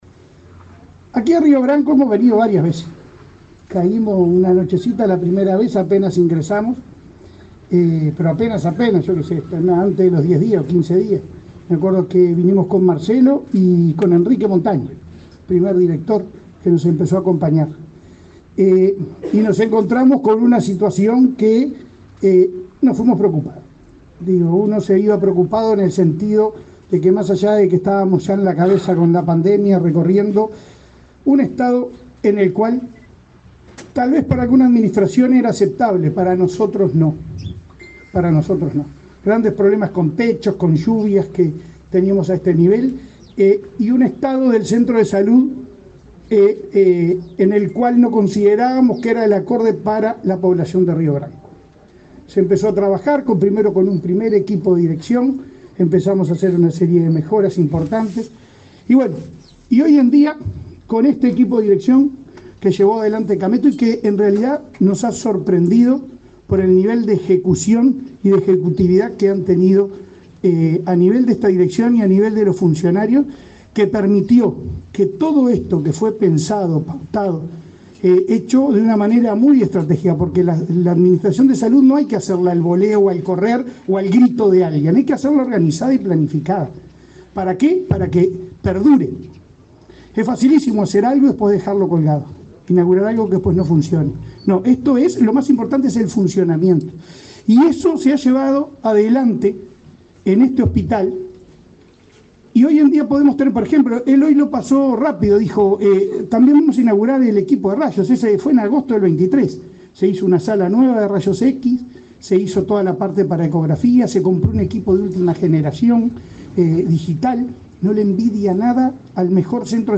Palabras del presidente de ASSE, Leonardo Cipriani
Palabras del presidente de ASSE, Leonardo Cipriani 06/05/2024 Compartir Facebook X Copiar enlace WhatsApp LinkedIn Este lunes 6, el presidente de la Administración de los Servicios de Salud del Estado (ASSE), Leonardo Cipriani, encabezó la inauguración del block quirúrgico del hospital Eduardo Guerra Méndez, en la localidad de Río Branco, departamento de Cerro Largo.